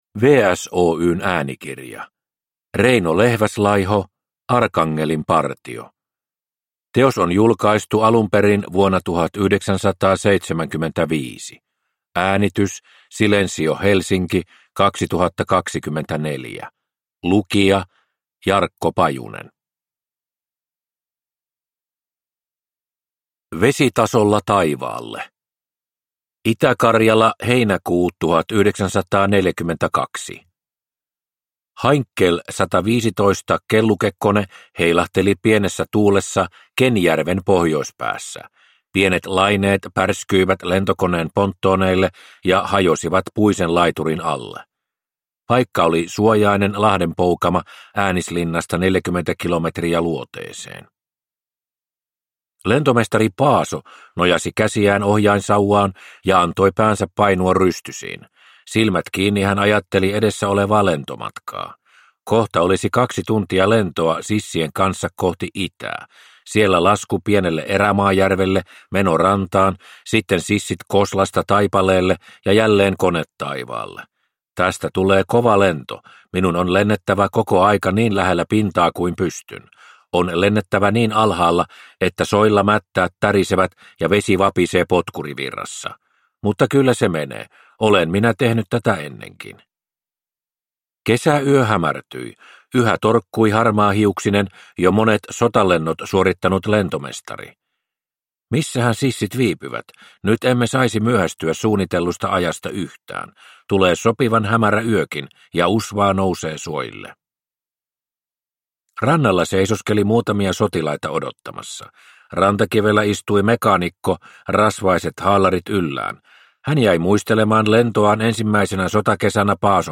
Arkangelin partio – Ljudbok